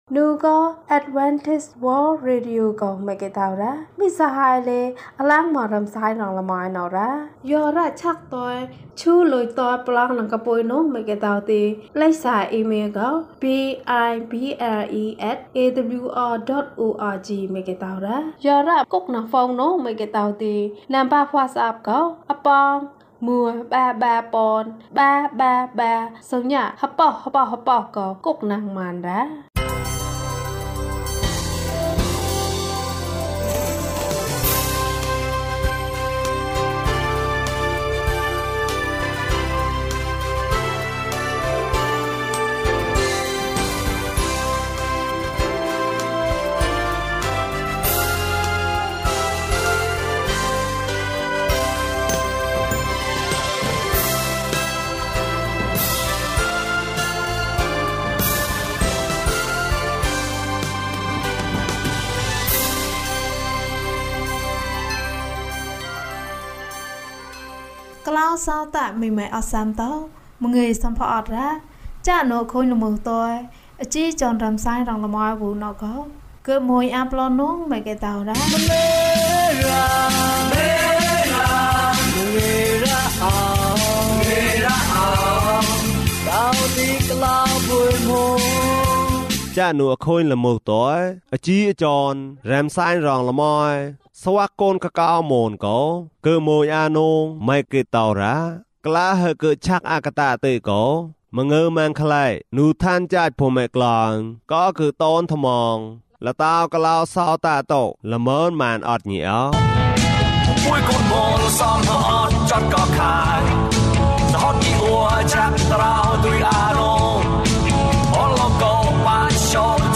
ဆည်းကပ်ခြင်းနဲ့ ကလေးပုံပြင်။ ဓမ္မသီချင်း။ တရားဒေသနာ။